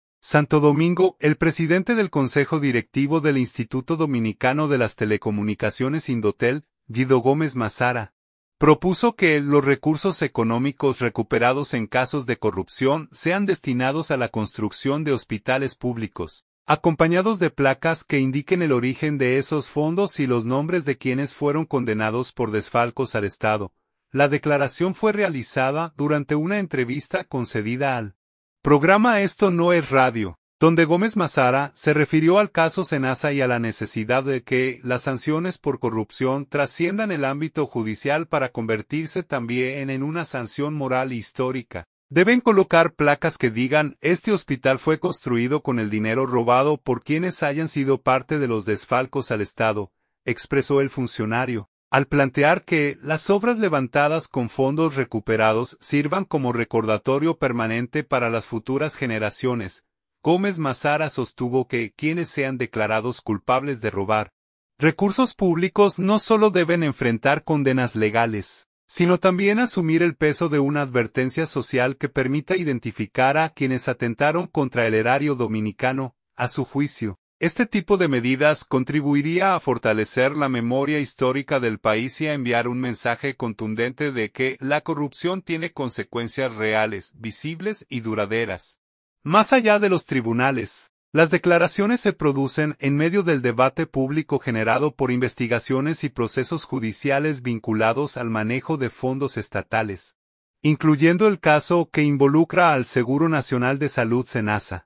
La declaración fue realizada durante una entrevista concedida al programa Esto No Es Radio, donde Gómez Mazara se refirió al caso Senasa y a la necesidad de que las sanciones por corrupción trasciendan el ámbito judicial para convertirse también en una sanción moral e histórica.